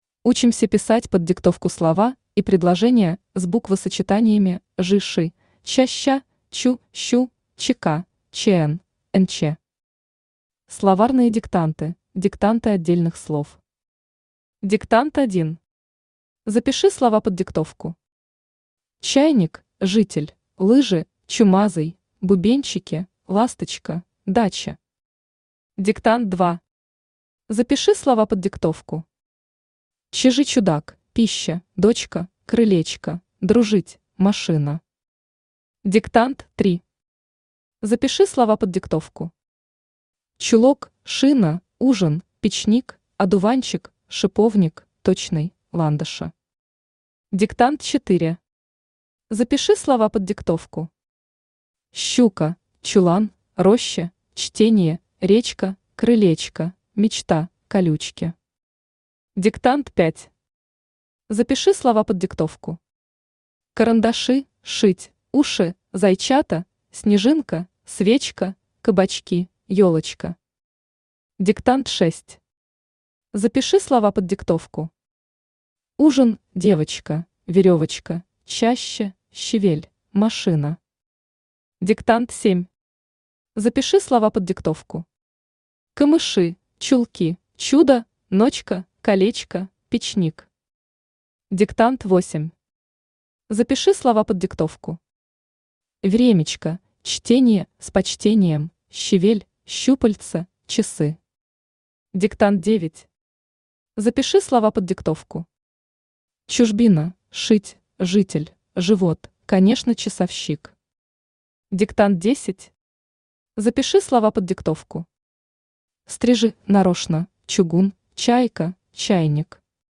Аудиокнига Диктанты одной орфограммы. Буквосочетания жи-ши, ча-ща, чу-щу, чк, чн, нч | Библиотека аудиокниг
Буквосочетания жи-ши, ча-ща, чу-щу, чк, чн, нч Автор Татьяна Владимировна Векшина Читает аудиокнигу Авточтец ЛитРес.